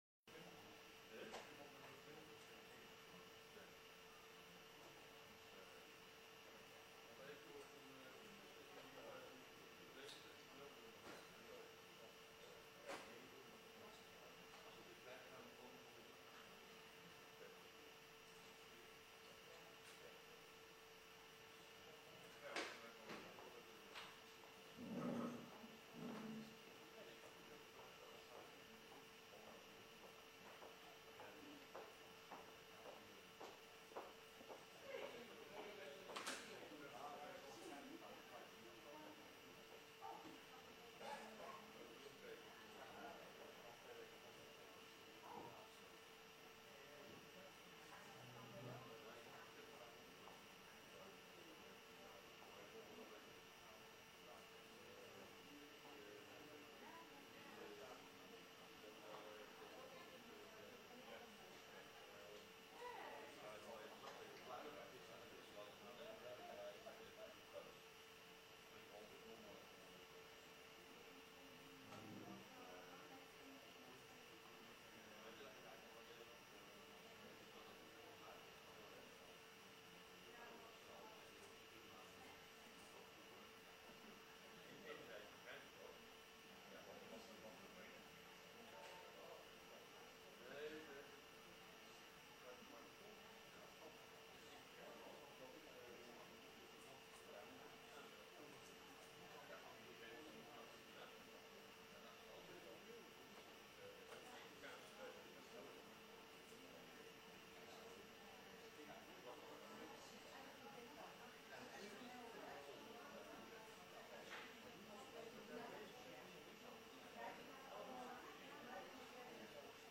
Locatie: Commissiekamer 1